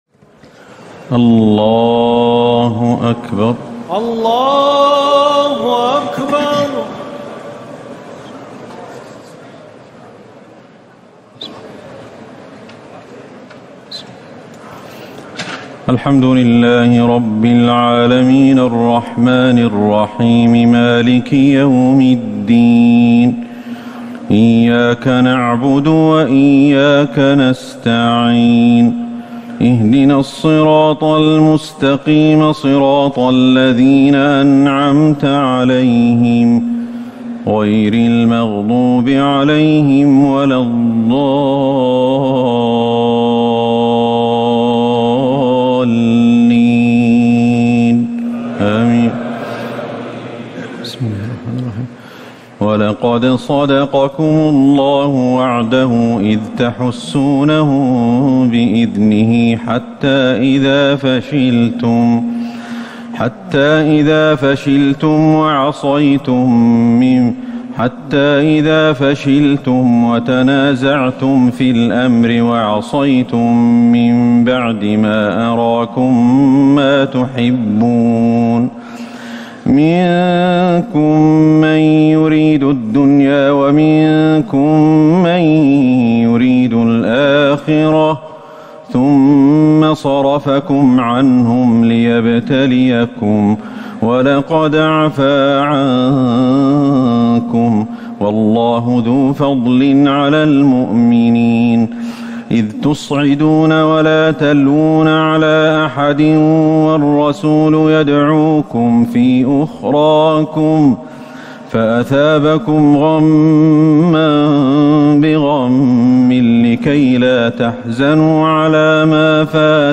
تراويح الليلة الرابعة رمضان 1439هـ من سورتي آل عمران (152-200) و النساء (1-18) Taraweeh 4 st night Ramadan 1439H from Surah Aal-i-Imraan and An-Nisaa > تراويح الحرم النبوي عام 1439 🕌 > التراويح - تلاوات الحرمين